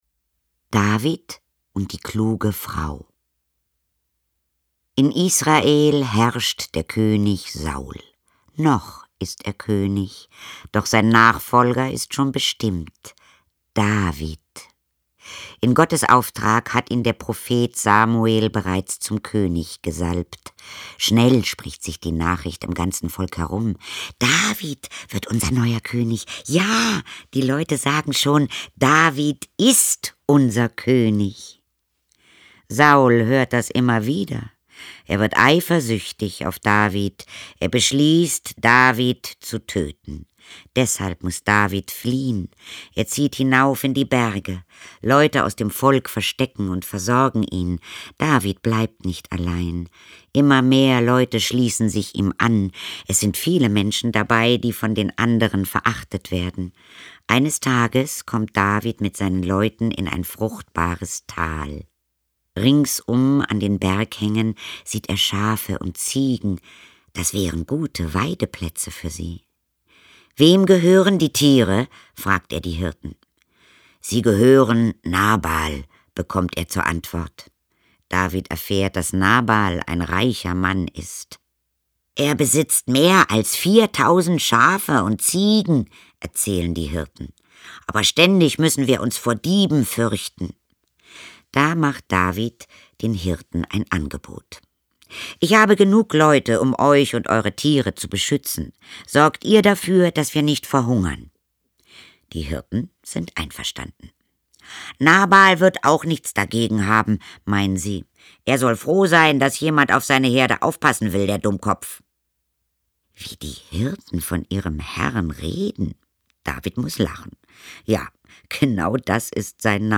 Ulrich Noethen, Katharina Thalbach (Sprecher)
Auf dieser CD lesen Dir Katharina Thalbach und Ulrich Noethen drei Geschichten über den König David vor: "David und Goliat", "David und die kluge Frau" und "David wird König".
Mit Liedern zum Nachdenken und Mitmachen.